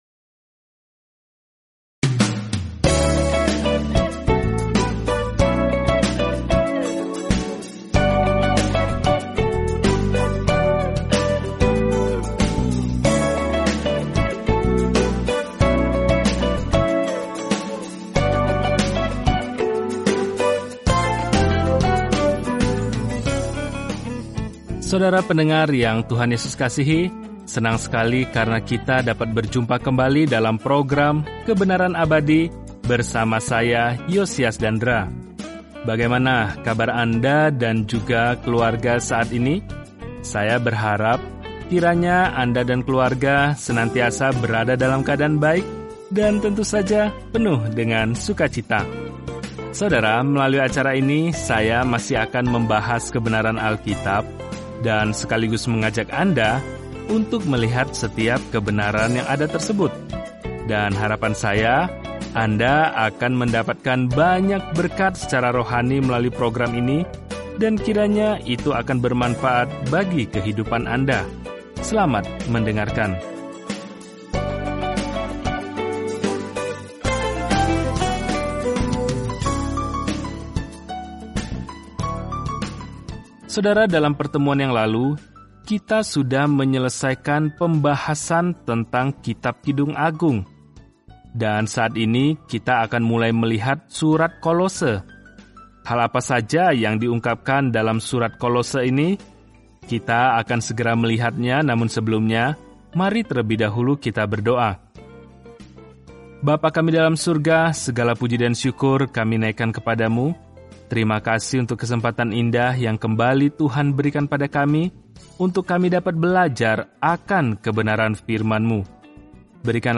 Firman Tuhan, Alkitab Kolose 1:1-2 Mulai Rencana ini Hari 2 Tentang Rencana ini “Utamakanlah Yesus” adalah fokus dari surat kepada jemaat Kolose, yang menawarkan bantuan bagaimana berjalan dalam identitas penuh dengan Kristus. Jelajahi Kolose setiap hari sambil mendengarkan pelajaran audio dan membaca ayat-ayat tertentu dari firman Tuhan.